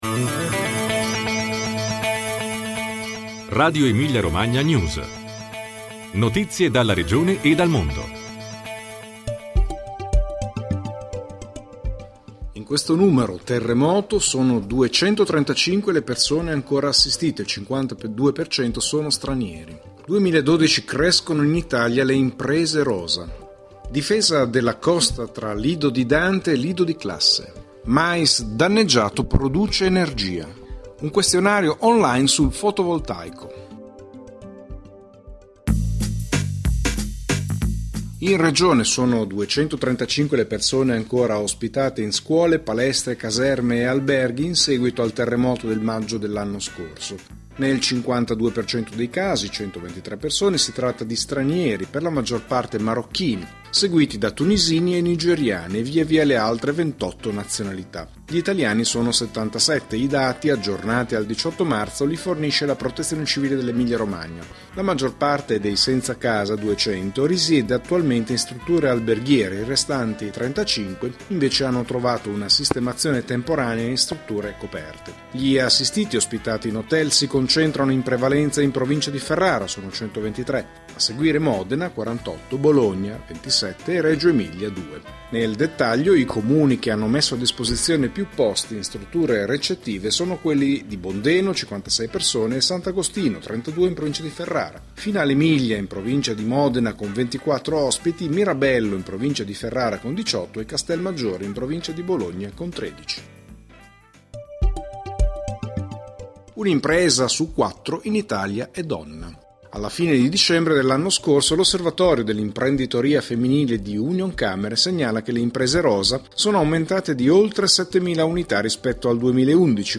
Notizie dalla Regione e dal mondo